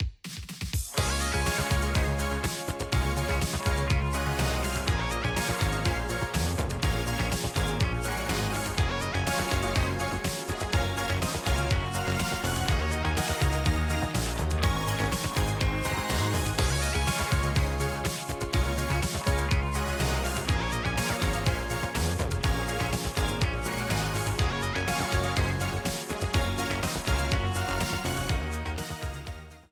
A purple streamer theme
Ripped from the game
clipped to 30 seconds and applied fade-out